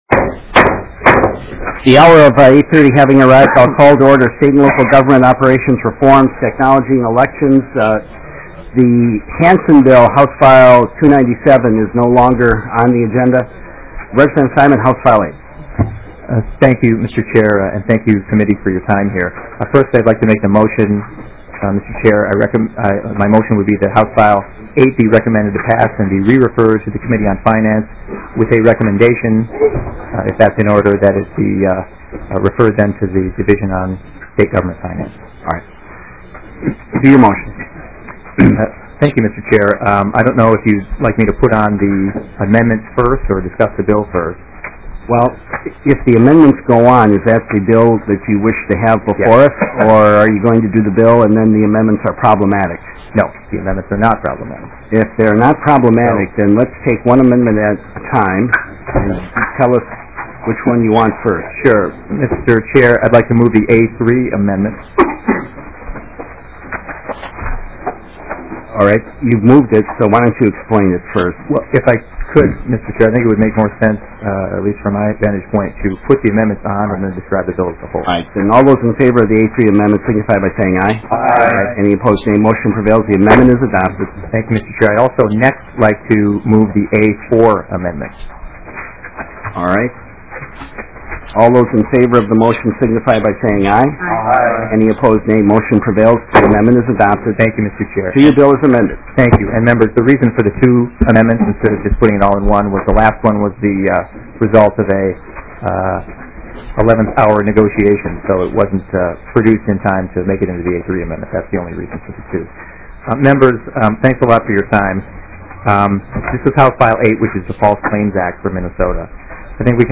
State and Local Government Operations Reform, Technology and Elections EIGHTH MEETING - Minnesota House of Representatives